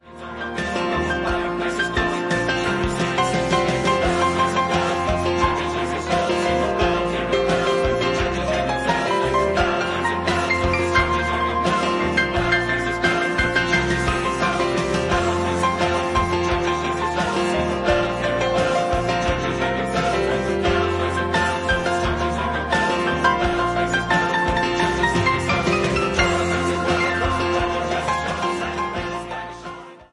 The live piano accompaniment that plays with the album